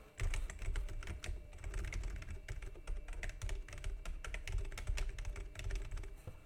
Dźwięki klawiszy Genesis Thor 303 TKL
Genesis-Thor-303-dzwieki-klawiszy.mp3